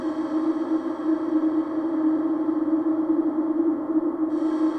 Index of /musicradar/sparse-soundscape-samples/Creep Vox Loops
SS_CreepVoxLoopB-07.wav